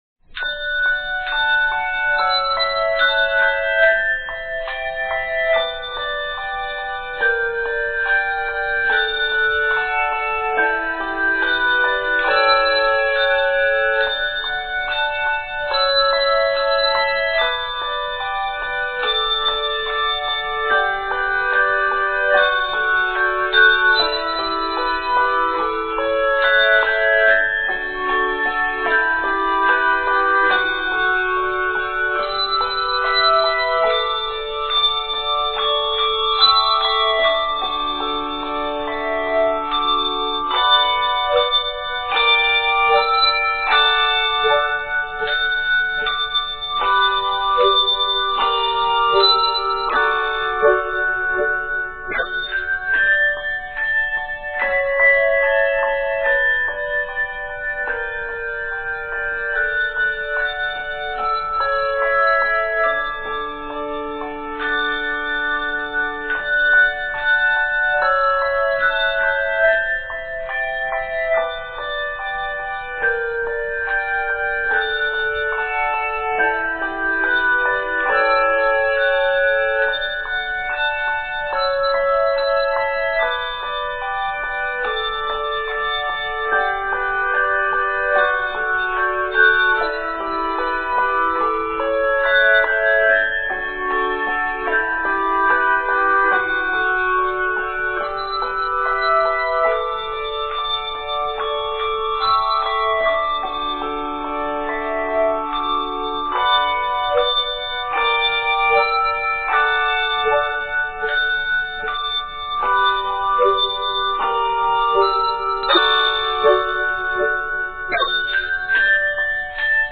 handbells
Watch for bell changes and syncopation.